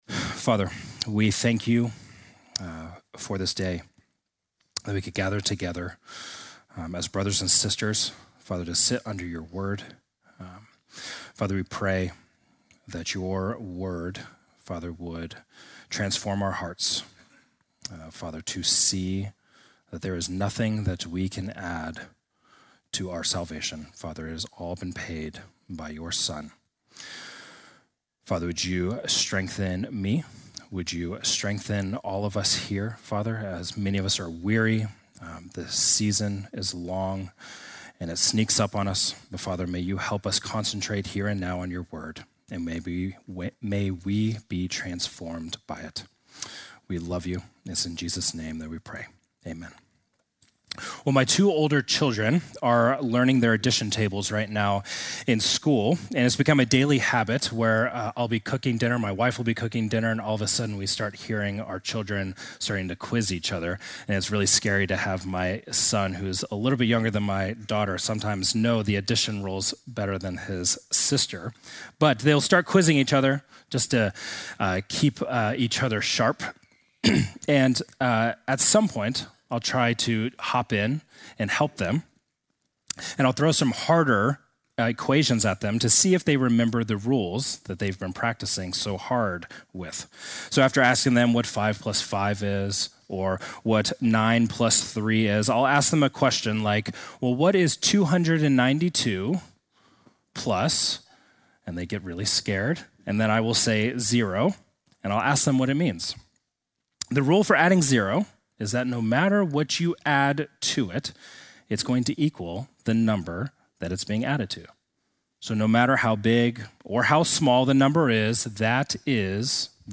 Sunday morning message December 21, 2025: